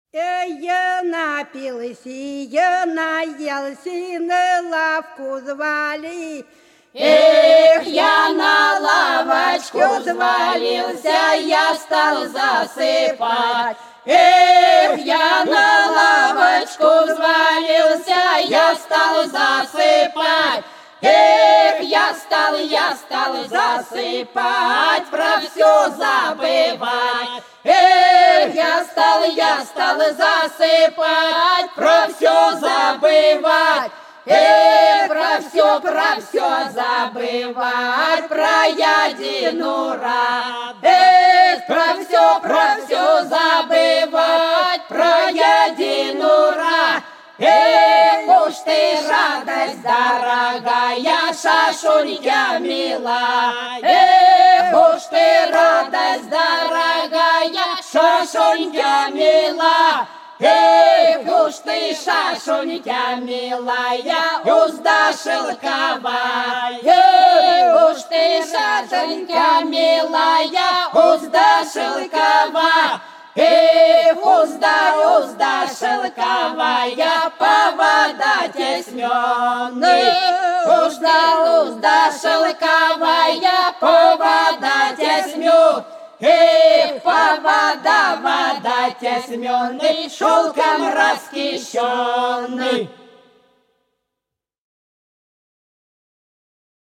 За речкою диво Я напился, я наелся - плясовая (с. Гвазда)
11_Я_напился,_я_наелся_-_плясовая.mp3